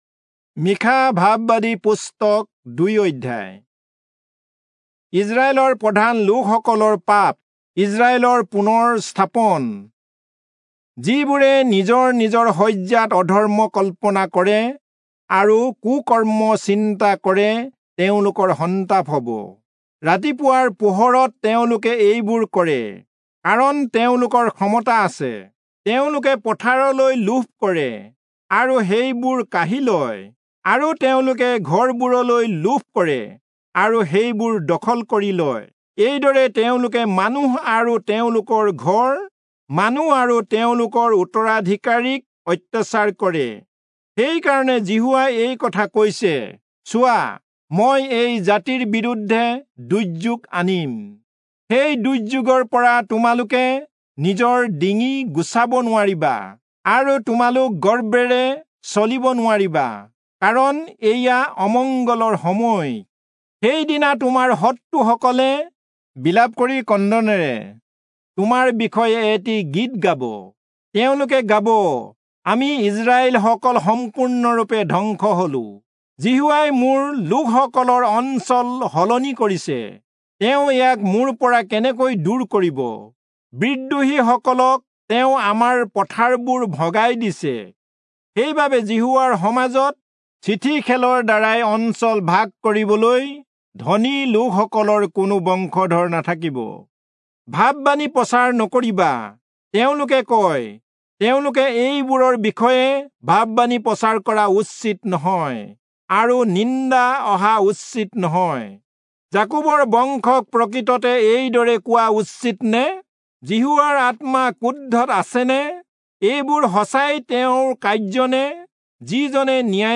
Assamese Audio Bible - Micah 7 in Akjv bible version